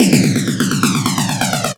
F X     49.wav